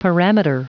Prononciation du mot parameter en anglais (fichier audio)
Prononciation du mot : parameter